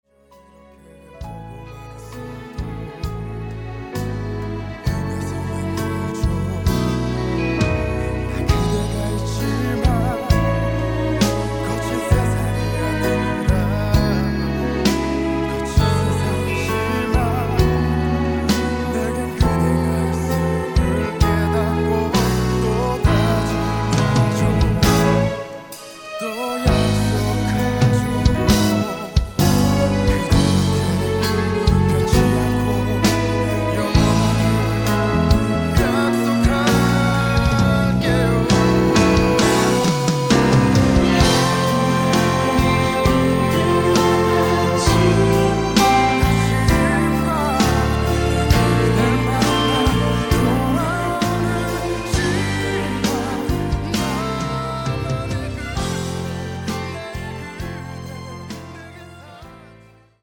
음정 원키 5:37
장르 가요 구분 Voice MR